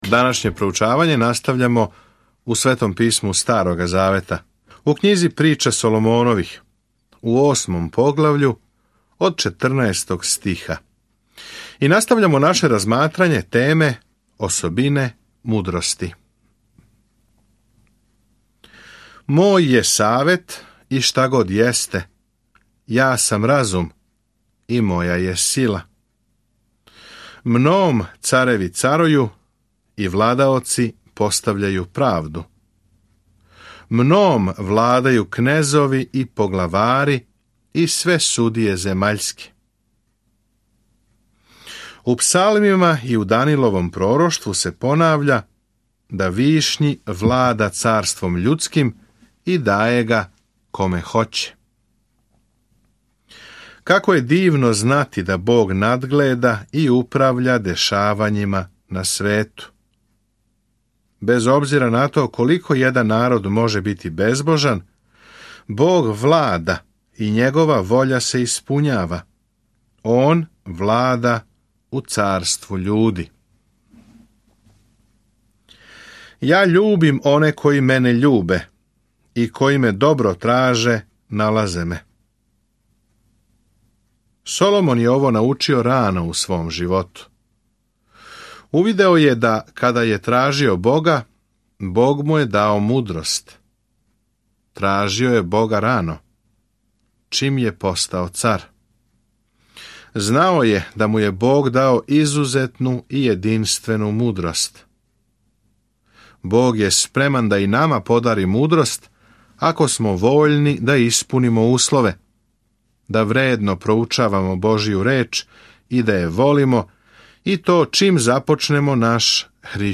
Sveto Pismo Priče Solomonove 8:14-36 Priče Solomonove 9:1-10 Dan 11 Započni ovaj plan Dan 13 O ovom planu Пословице су кратке реченице извучене из дугог искуства које поучавају истину на начин који се лако памти – истине које нам помажу да доносимо мудре одлуке. Свакодневно путујте кроз Пословице док слушате аудио студију и читате одабране стихове из Божје речи.